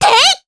Cleo-Vox_Attack2_jp.wav